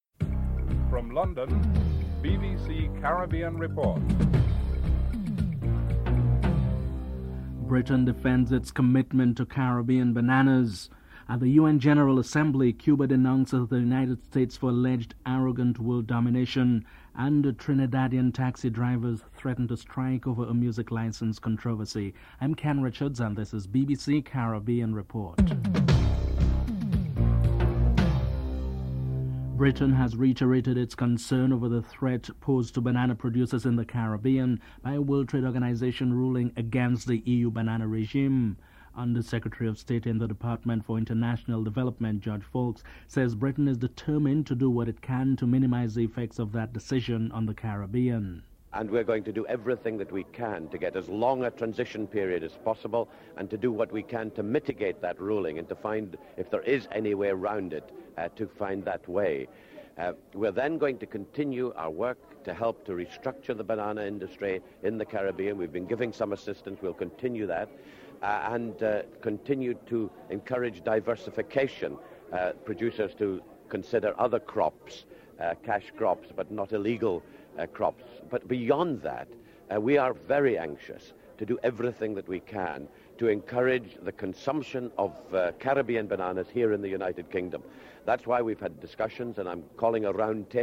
Britain defends it commitment to Caribbean bananas. George Foulkes, Under-Secretary of State in the Department for International Development, and Former Prime Minister Vaughn Lewis are interviewed.